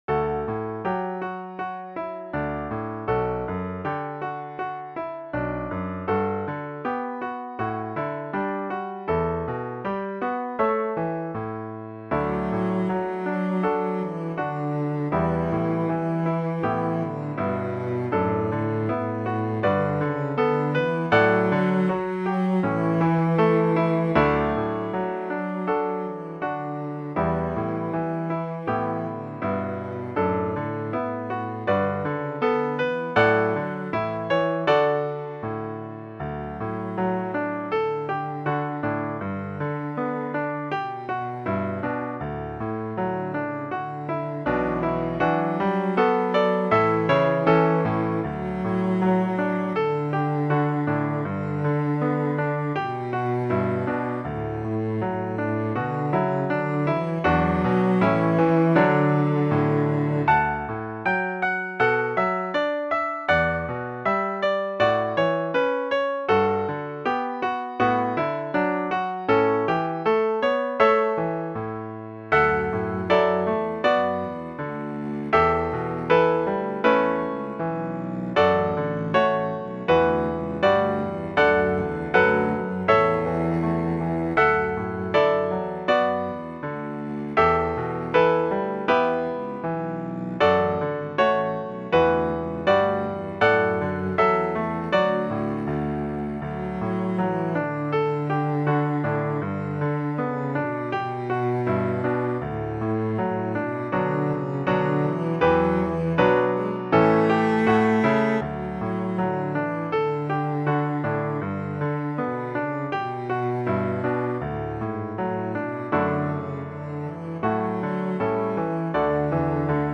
Intermediate Instrumental Solo with Piano Accompaniment.
Christian, Gospel, Sacred.
puts the sacred theme to a gentle, meditative mood.